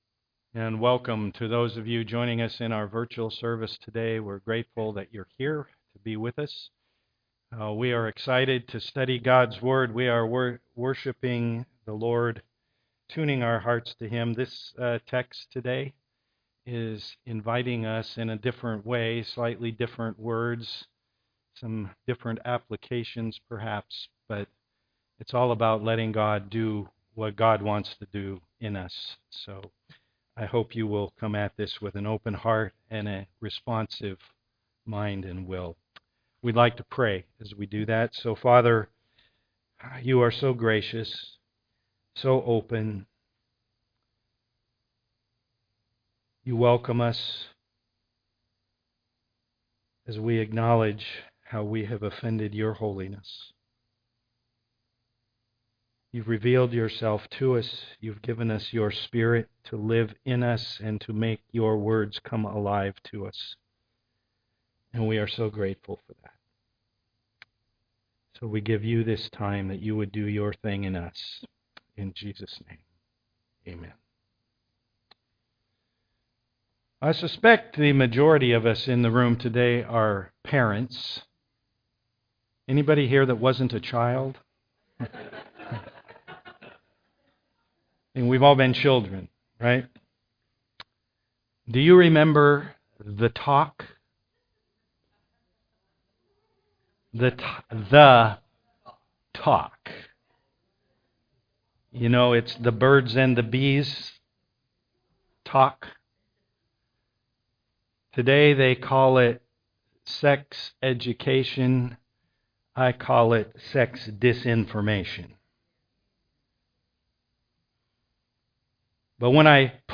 1 Thessalonians 4:1-8 Service Type: am worship Sometimes we get the proverbial cart before the horse.